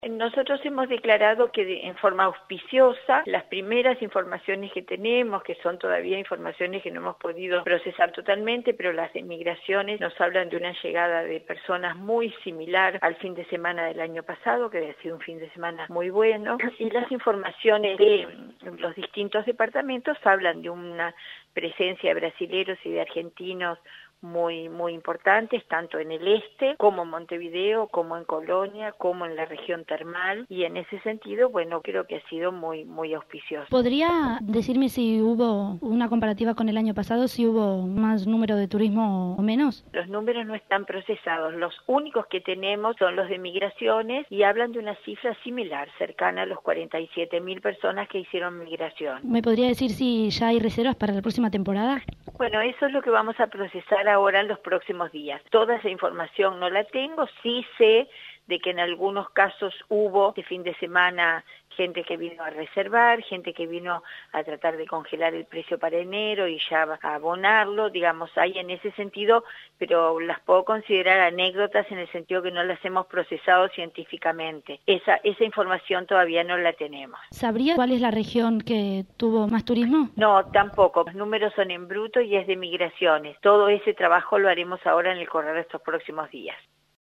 La ministra de Turismo, Liliam Kechichián, indicó a 970 Noticias que la llegada de visitantes este fin de semana largo fue muy auspiciosa.